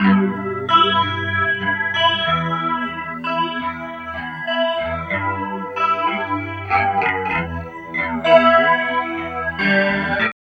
29 GUIT 3 -L.wav